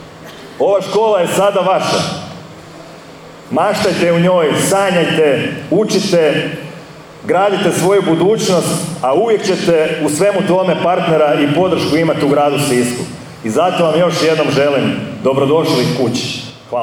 “Ovo nije samo nova zgrada već simbol obnove, ustrajnosti i brige za budućnost našeg grada“istaknuo je gradonačelnik Orlić, posebno se obraćajući učenicima škole